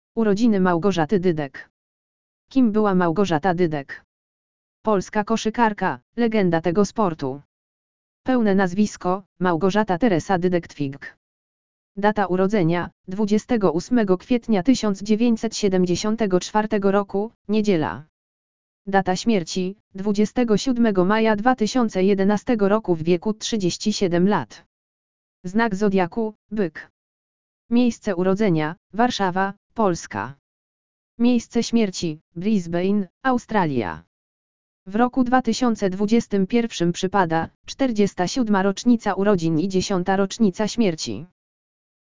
lektor_audio_urodziny_malgorzaty_dydek.mp3